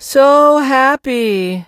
sandy_lead_vo_04.ogg